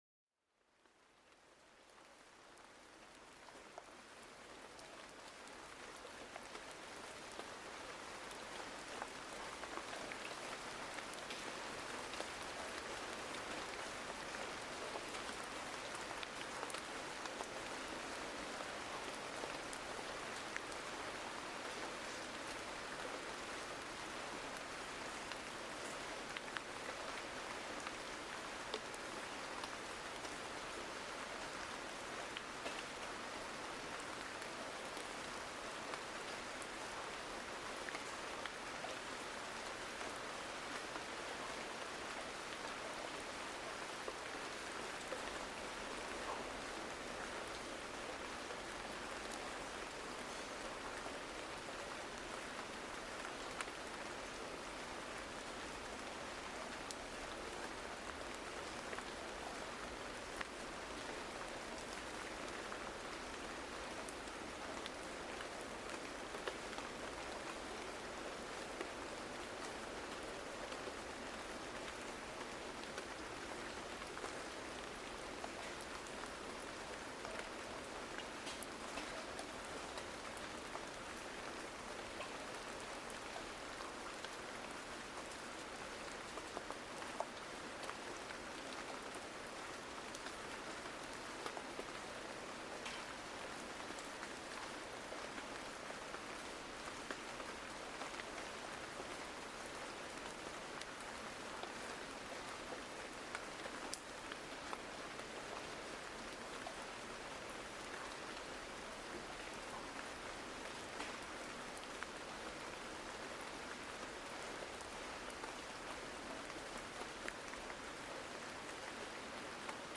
Sonidos de Lluvia: Relájate y Duerme Mejor
Sumérgete en la serenidad con el suave sonido de la lluvia. Este episodio te transportará a un mundo de calma donde cada gota te calmará.